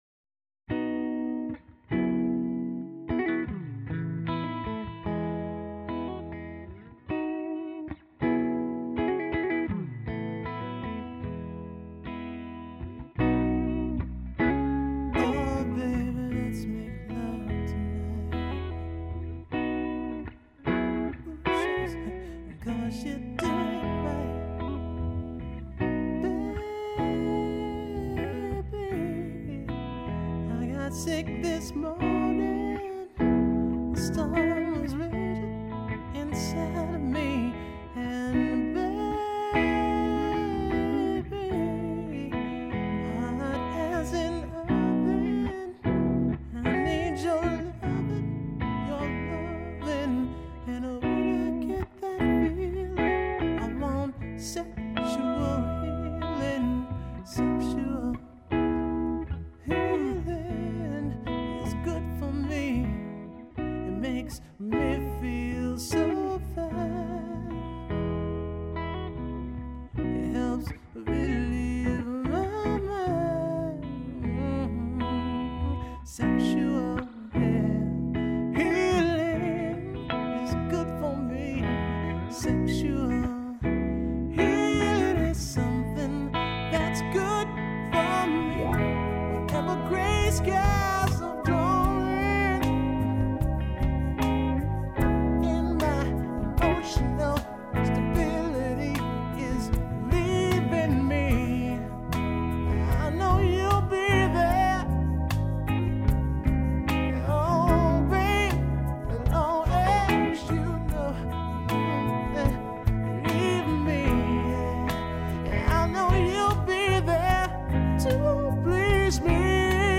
I still don't like the vocal delivery, but oh well...